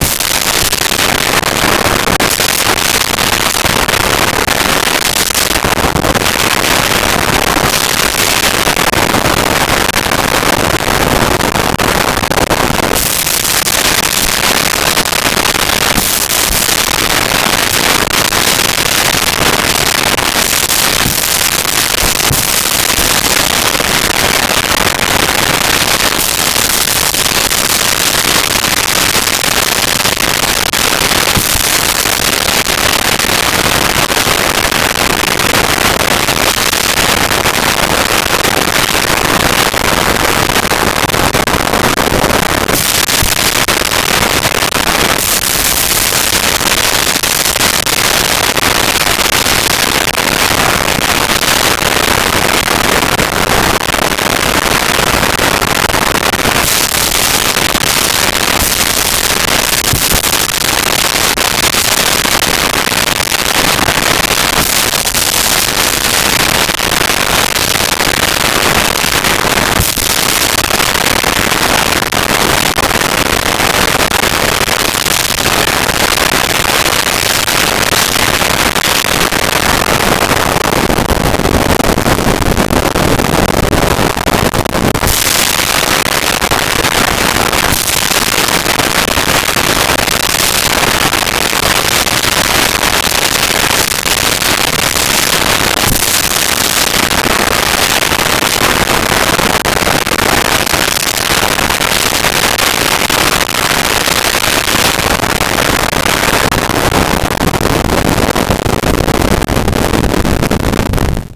Combat Distant Explosions
Combat Distant Explosions.wav